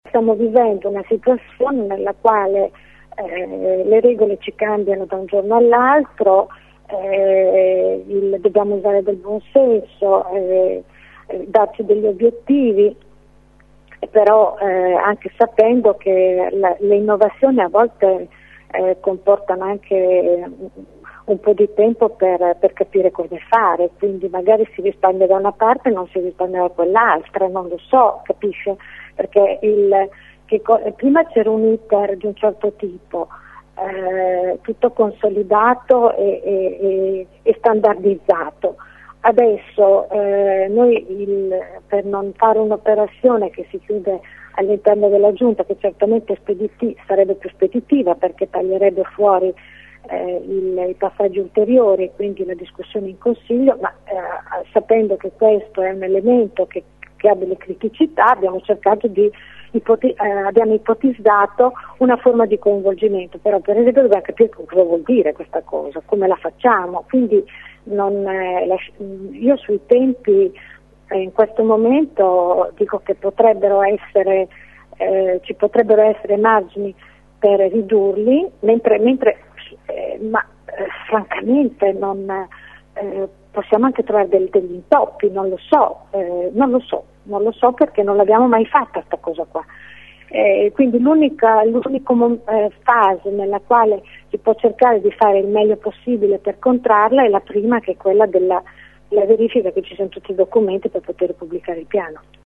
Impossibile per ora sapere quanto tempo si riuscirà a risparmiare rispetto all’iter tradizionale, spiega l’assessore: